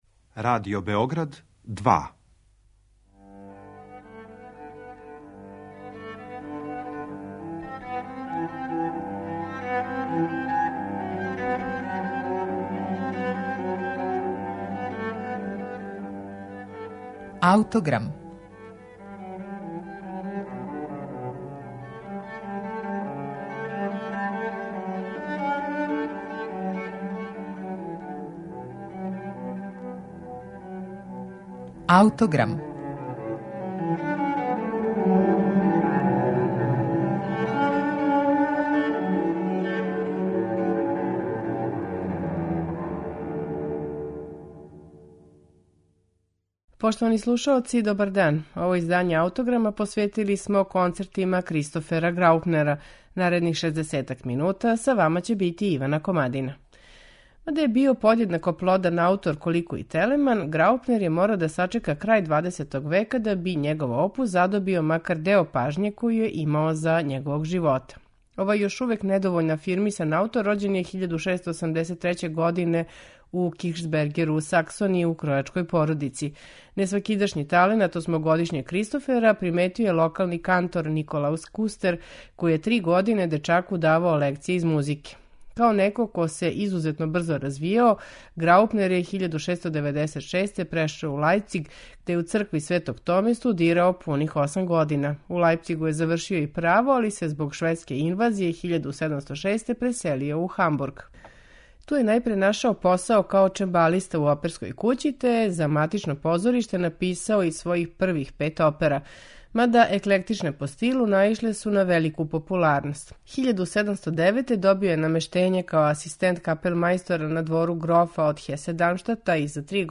Сви концерти су писани за релативно мале ансамбле и нису били намењени посебно свечаним приликама.